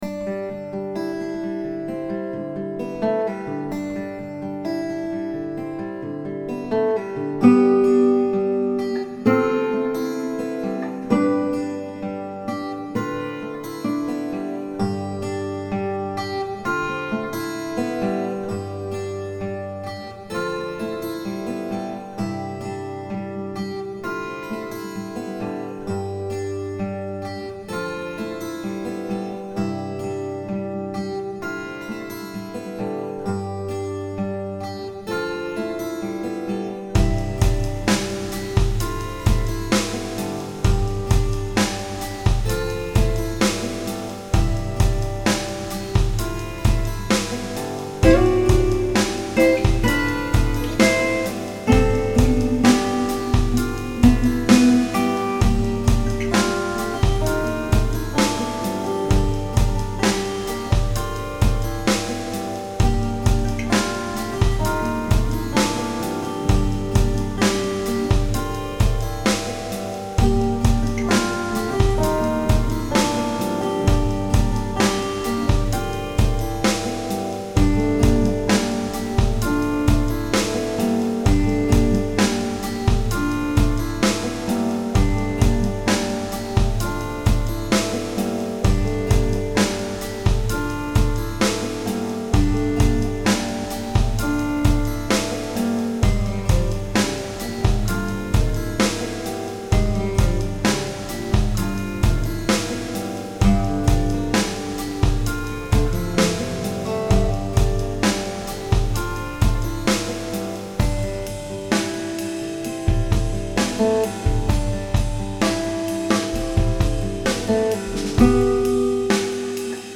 soundscape sound scape fx special fx